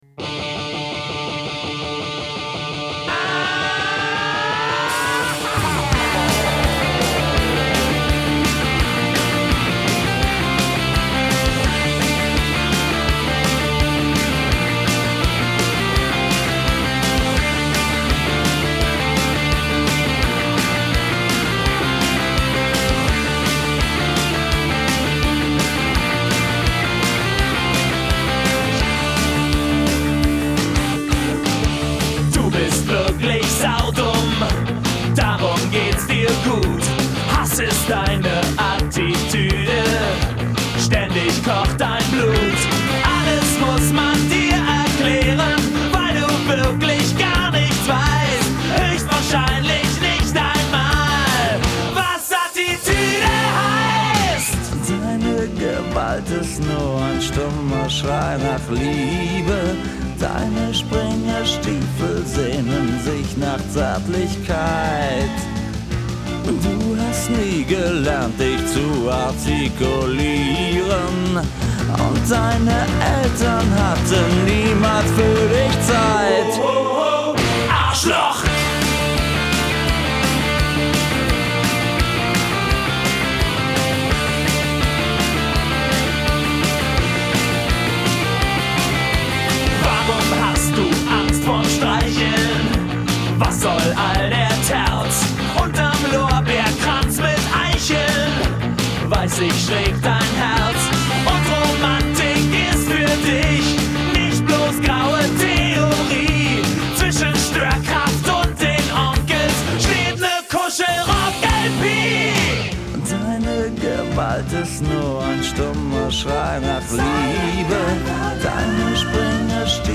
Rock GER